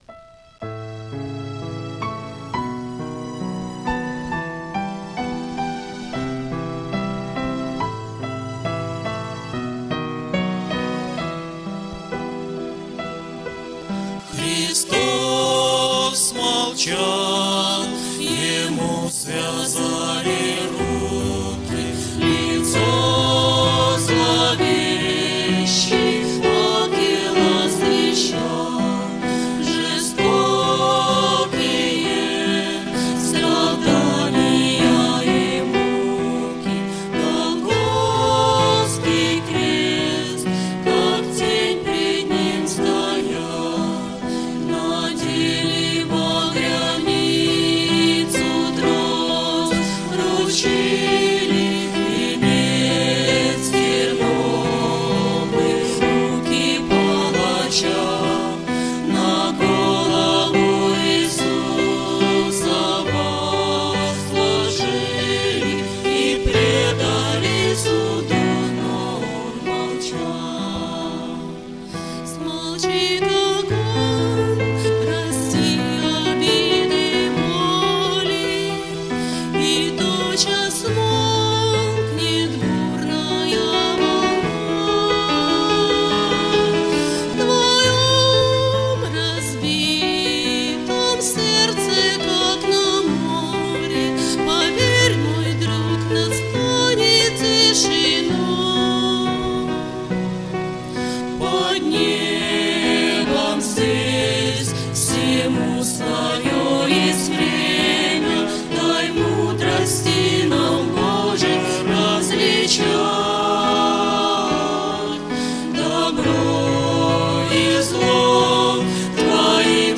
• Исполняет: Группа
• Жанр: Авторская песня